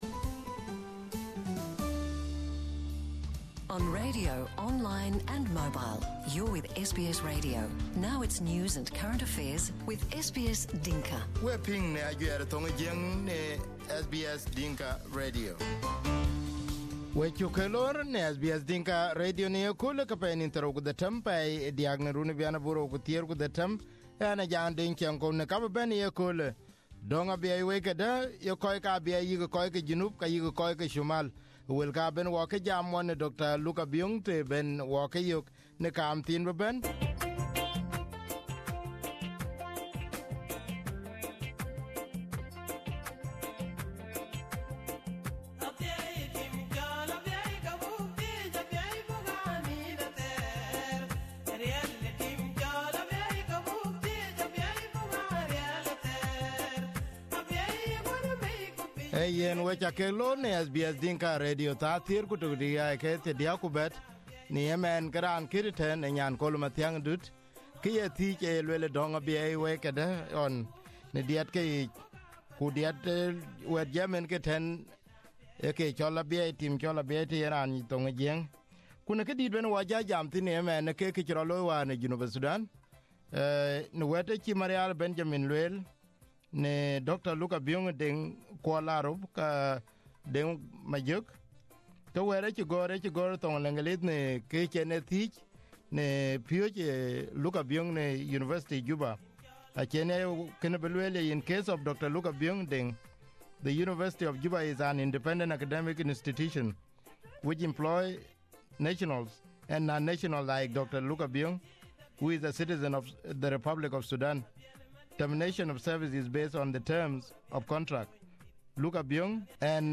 During an interview with SBS Dinka Radio, Dr Biong said how he valued Dr Marial's apology.